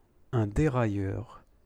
pronounce each phrase.